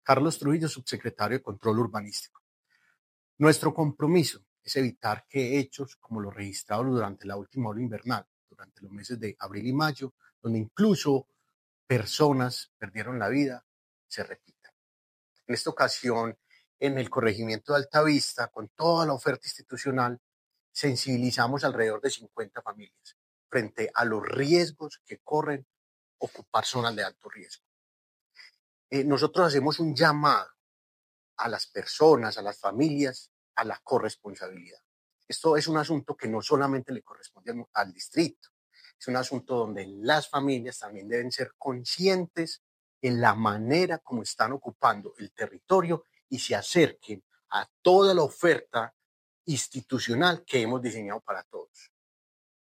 Declaraciones del subsecretario de control urbanístico, Carlos Trujillo.
Declaraciones-del-subsecretario-de-control-urbanístico-Carlos-Trujillo..mp3